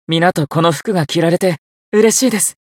觉醒语音 能和大家一起穿上这件衣服我很高兴 皆とこの服が着られて嬉しいです 媒体文件:missionchara_voice_622.mp3